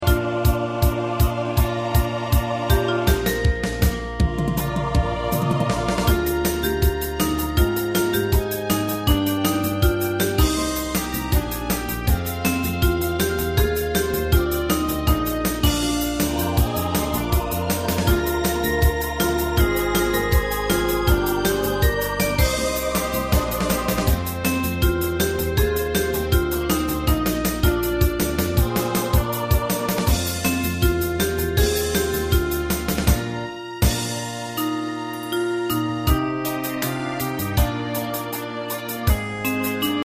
大正琴の「楽譜、練習用の音」データのセットをダウンロードで『すぐに』お届け！
カテゴリー: ユニゾン（一斉奏） .